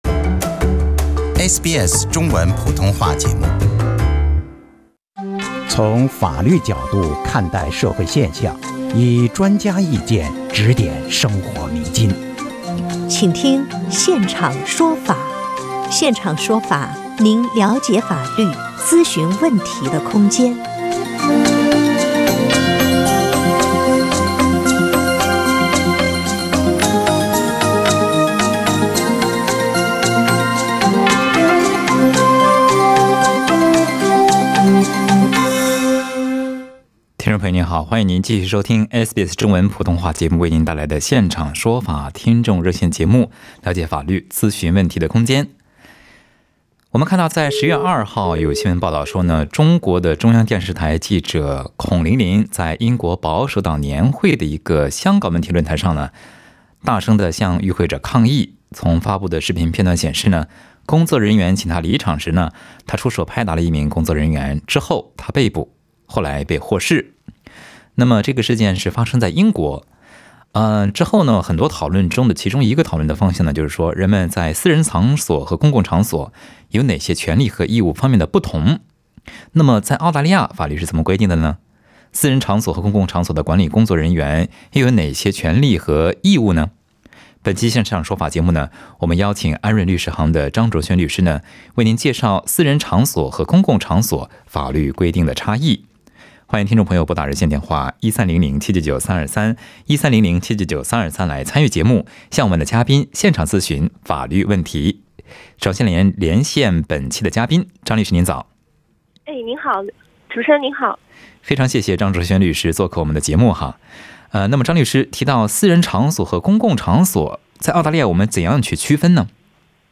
shared the legal knowledge in the talk-back.